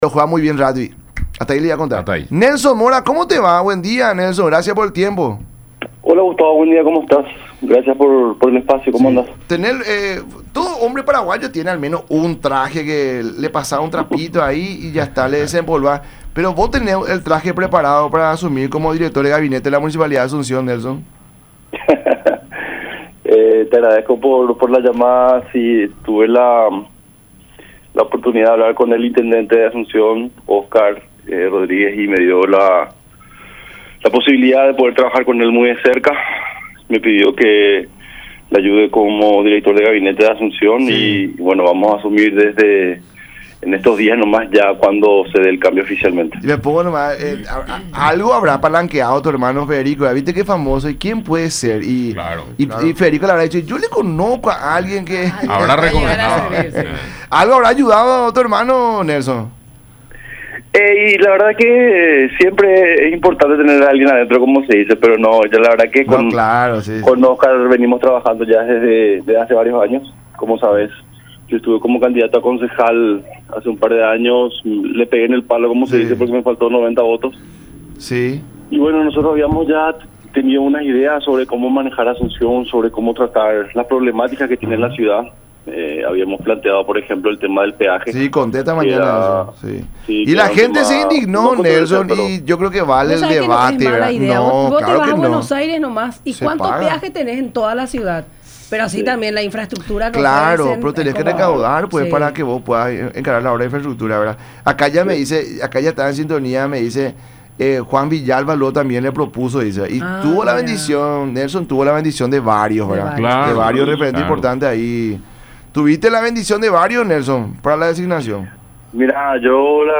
“Oscar Rodríguez me dio la oportunidad de trabajar de cerca con el, me pidió que le ayude como jefe de gabinete de La Municipalidad  Asunción y asumiremos en los próximos días”, mencionó Nelson Mora en charla con el programa “La Mañana De Unión” por Unión TV y radio La Unión.